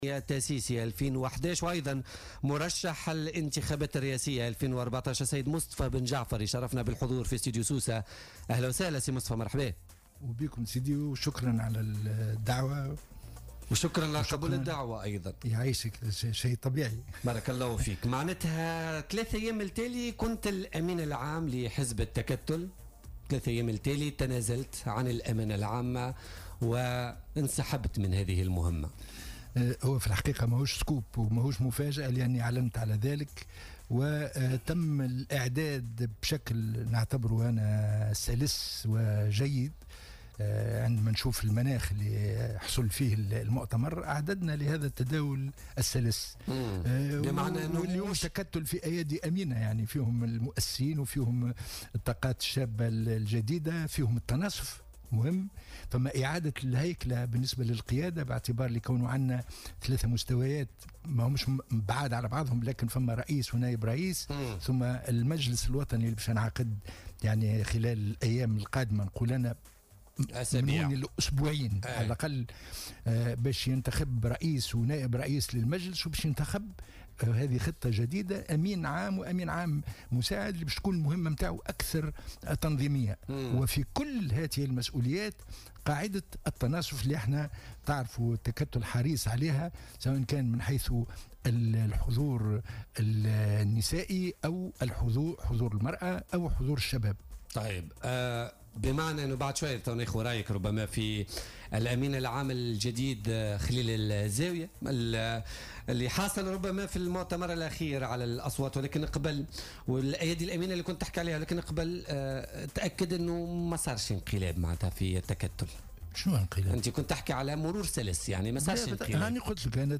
أكد الأمين العام السابق لحزب التكتل مصطفى بن جعفر ضيف بولتيكا اليوم الثلاثاء 12 سبتمبر 2017 أنه تم الاعداد بشكل سلس لمؤتمر التكتل الذي تم فيه انتخاب خليل الزاوية أمينا عاما معتبرا أن الحزب اليوم أصبح في اياد أمينة .